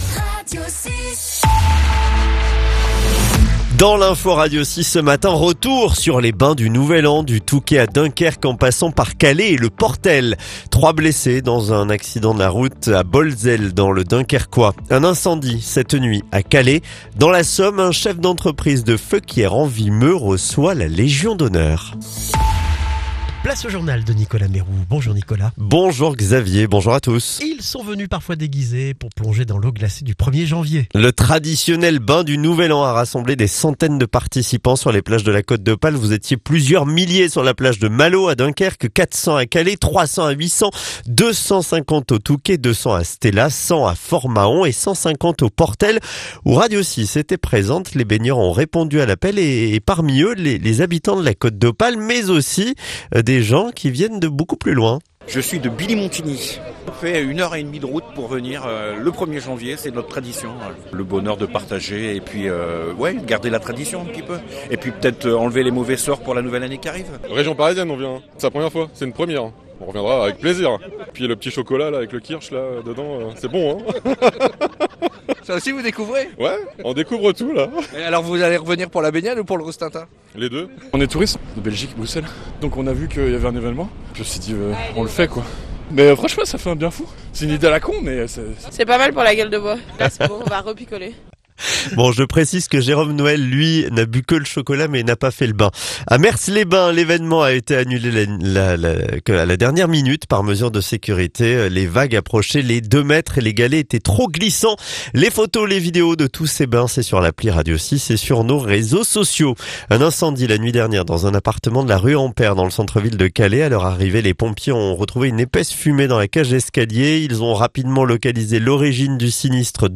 Le journal du vendredi 2 janvier